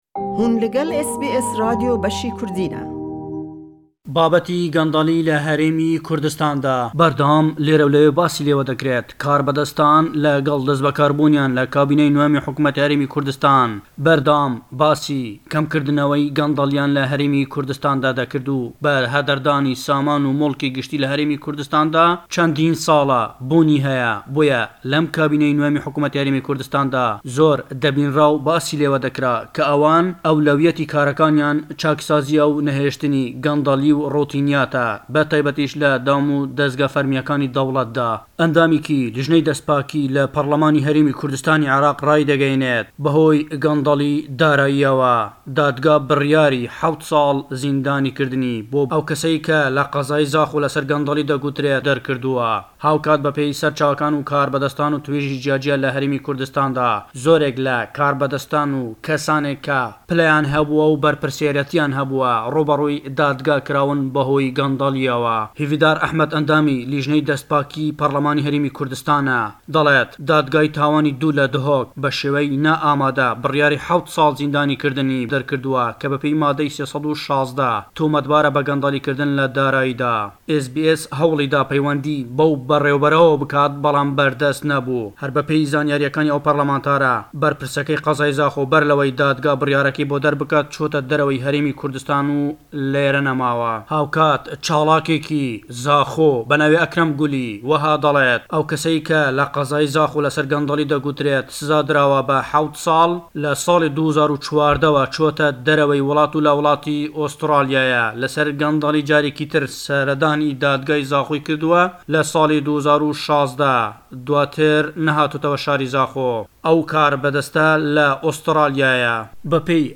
Le em Raporte da le Hewlêre we; berpirsêkî pêşûy şarî Zaxo ke hukum dirawe bo heft sall zindanî, bawer dekrêt êsta le Australya bijît.